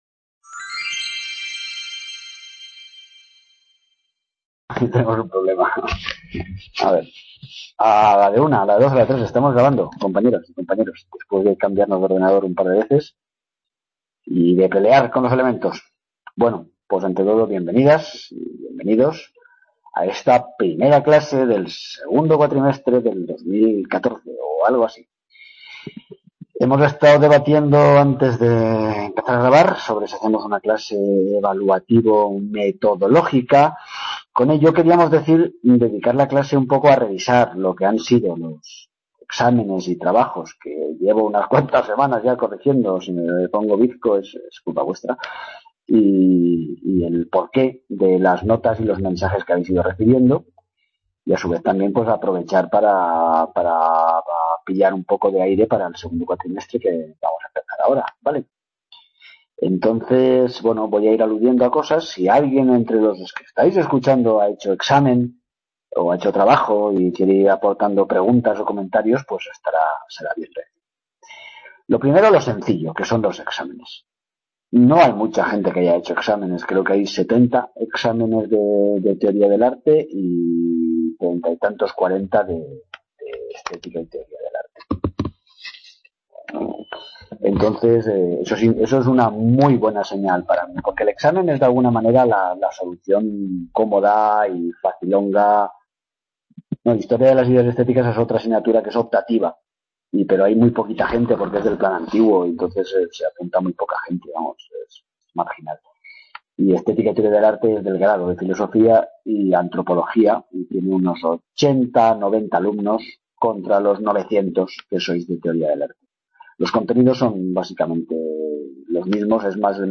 bienvenida metodológica, primera clase del segundo cuatrimestre del 2014, para darle vuelta y vuelta a los trabajos recibidos y pensar juntos en los que están por venir..